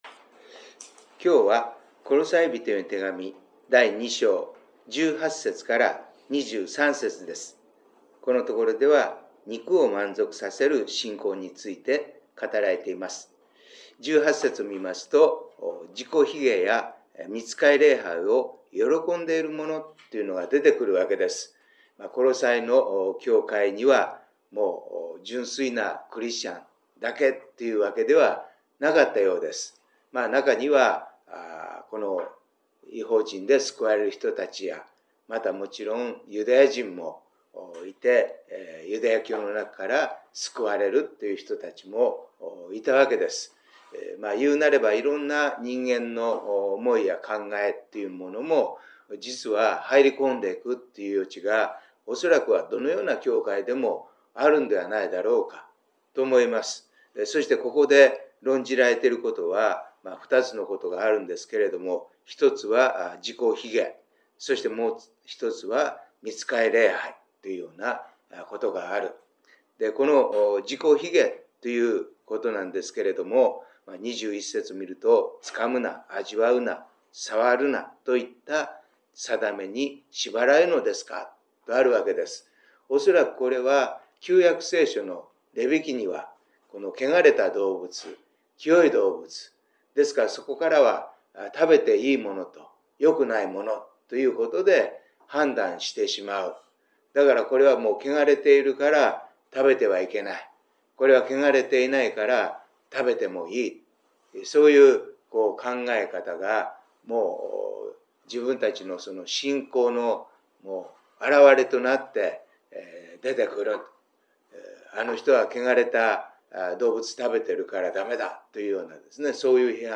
デボーションメッセージ│日本イエス・キリスト教団 柏 原 教 会